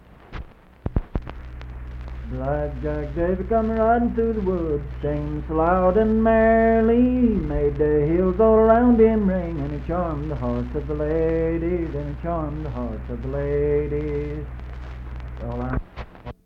Unaccompanied vocal music
Verse-refrain 1(5).
Ballads, Folk music--West Virginia, Coal miners--West Virginia
Voice (sung)